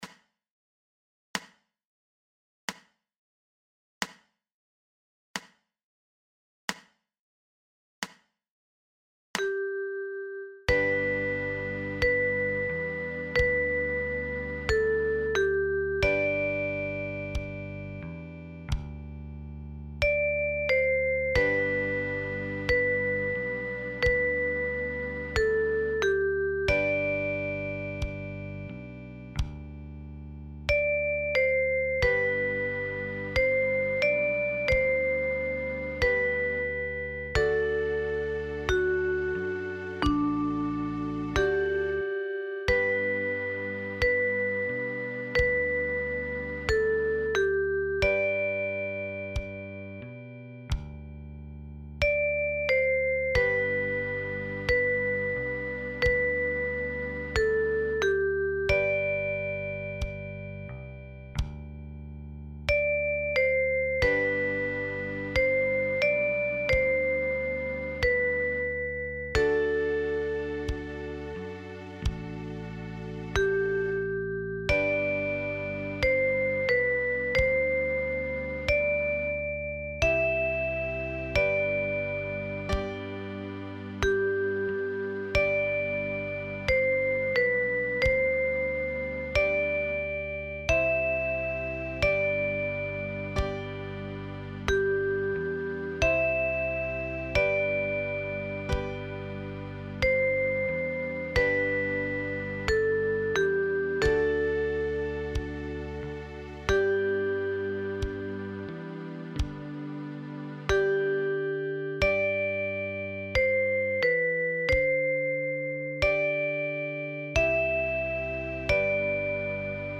Liederbuch (in deutsch und english) mit weiteren 33 bekannte Themen aus der Klassischen Musik – Band 3 – notiert für die Mandoline.
Die Lieder sind teilweise mehrstimmig (für eine/n SpielerInn) gesetzt und in Tabulatur und klassischer Notenschrift notiert.